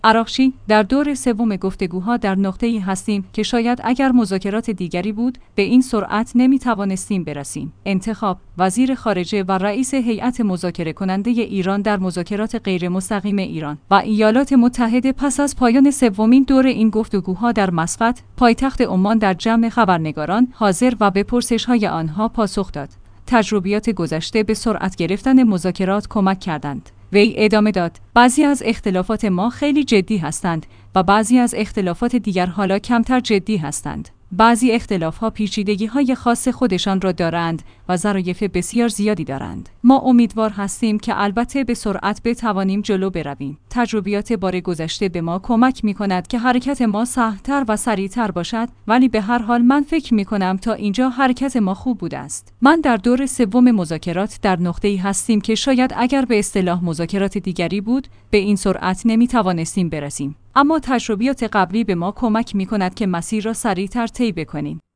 انتخاب/ وزیر خارجه و رئیس هیات مذاکره کننده ایران در مذاکرات غیرمستقیم ایران و ایالات متحده پس از پایان سومین دور این گفت‌وگوها در مسقط، پایتخت عمان در جمع خبرنگاران حاضر و به پرسش‌های آنها پاسخ داد.